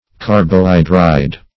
Carbohydride \Car`bo*hy"dride\, n.